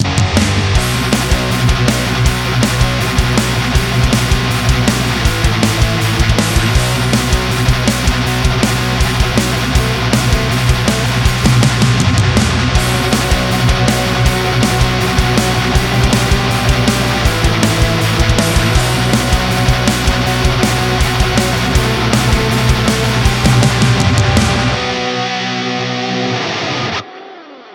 Hab heute Morgen diesen Riff aufgenommen.